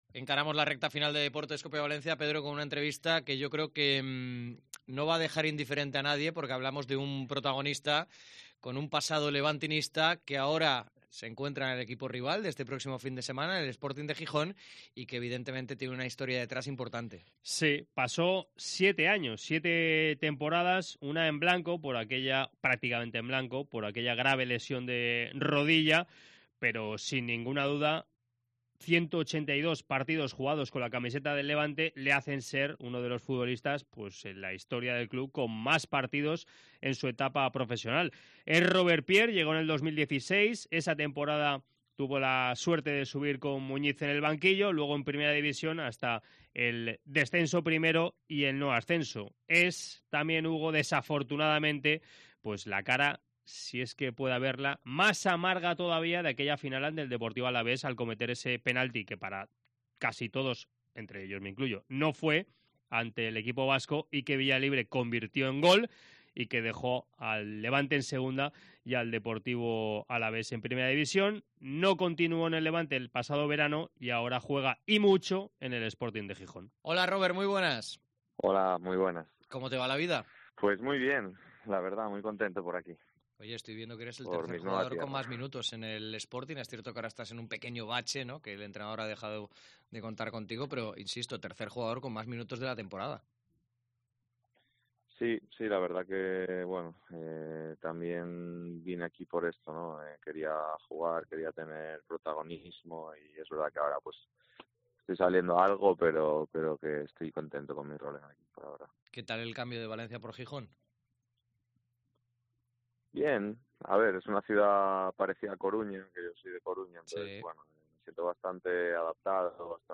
Entrevista de Rober Pier en Deportes COPE Valencia